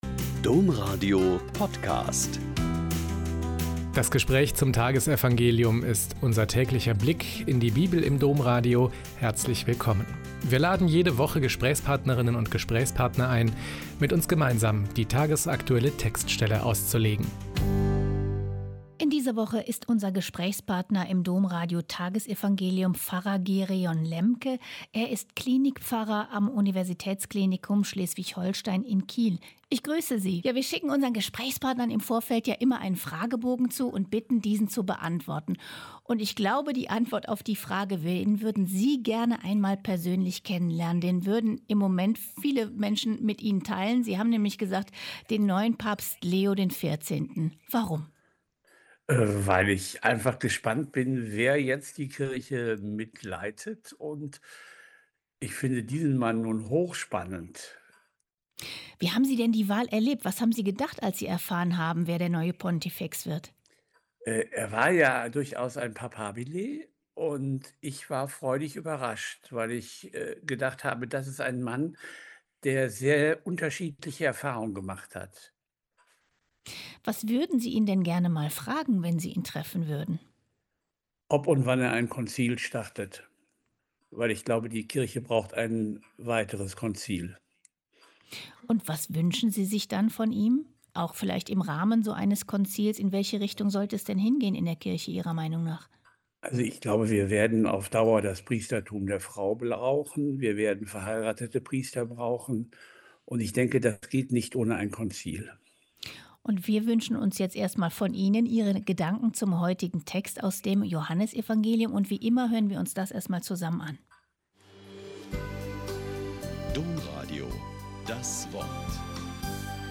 Joh 10,22-30 - Gespräch